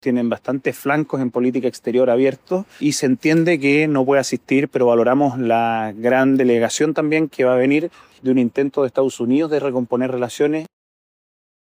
En la derecha, en tanto, atribuyen la ausencia principalmente al conflicto que mantiene Estados Unidos con Irán. De hecho, el diputado y jefe de bancada republicano, Benjamín Moreno, apuntó precisamente a ese factor.